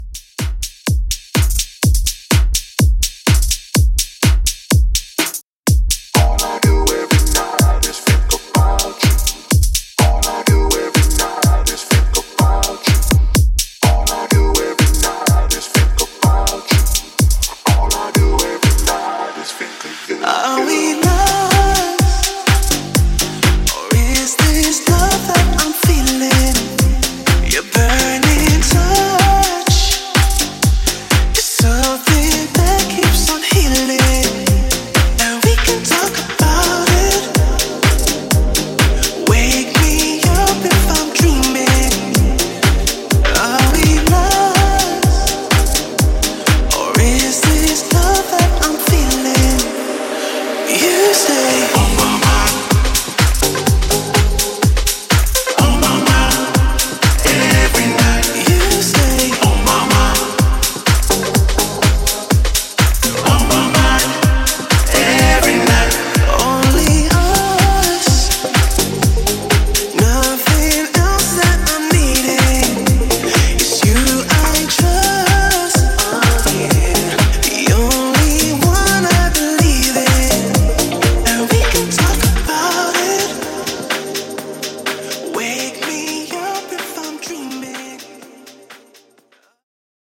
Extended Mix)Date Added